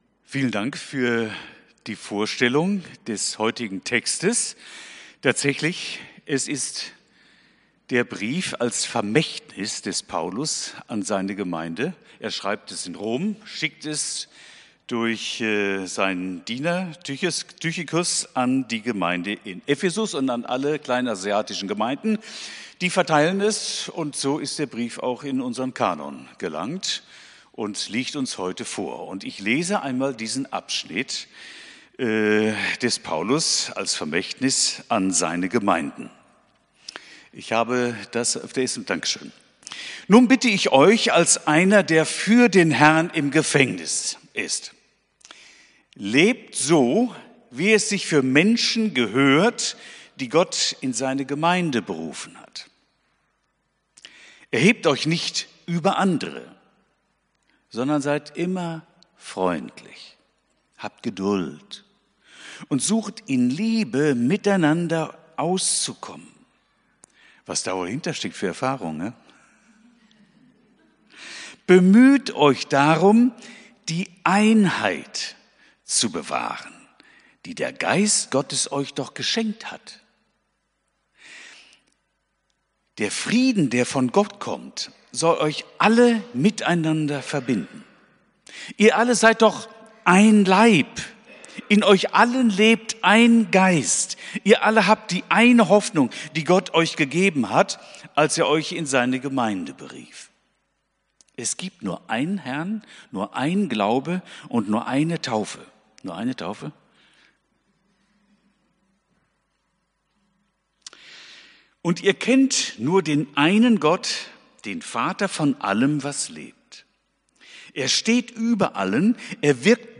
Predigt vom 31.08.2025